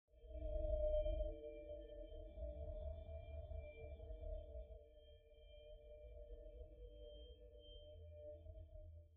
دانلود آهنگ جنگل از افکت صوتی طبیعت و محیط
جلوه های صوتی
دانلود صدای جنگل از ساعد نیوز با لینک مستقیم و کیفیت بالا